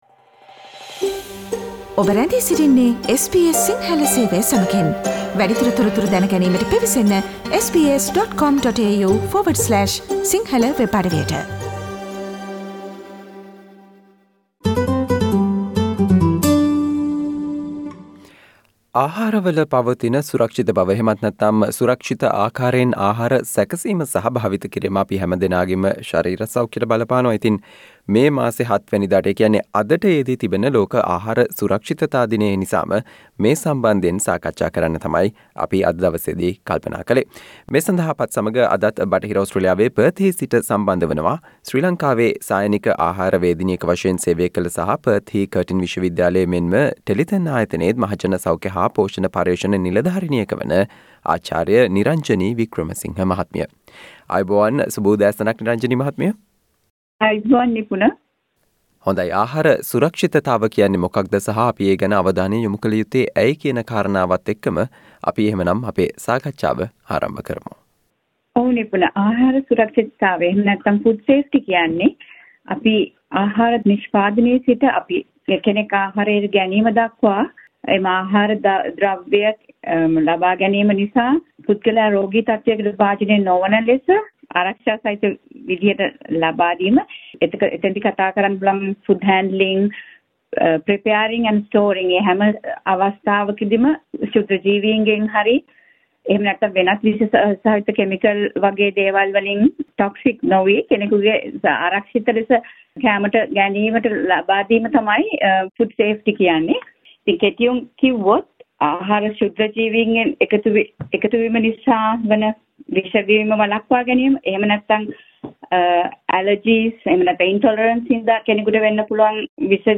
මැයි මස 7 වැනිදාට යෙදෙන ලෝක ආහාර සුරක්ෂිතතා දිනයට සමගාමීව, ආහාර සුරක්ෂිතතාවය සම්බන්ධයෙන් සහ ඒ පිළිබඳව අවධානය යොමුකළ යුත්තේ ඇයි යන කරුණු සම්බන්ධයෙන් වන සාකච්චාවට සවන්දෙන්න